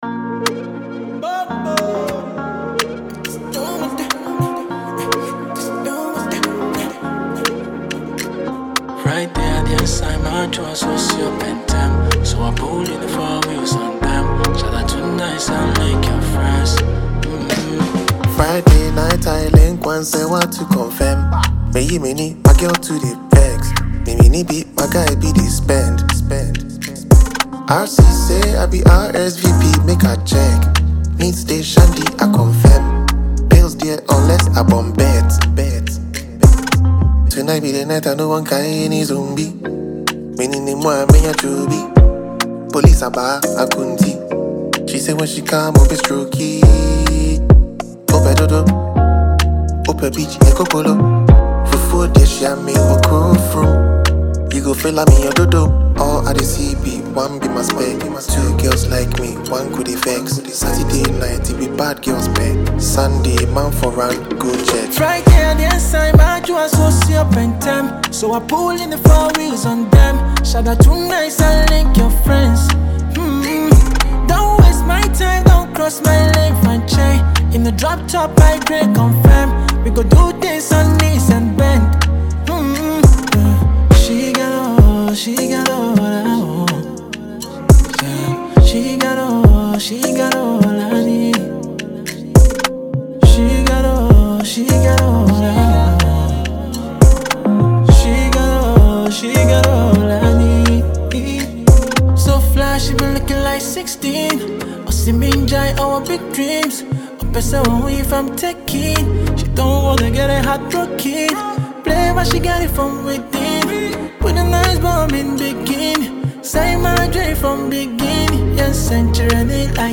remix song
Ghanaian rapper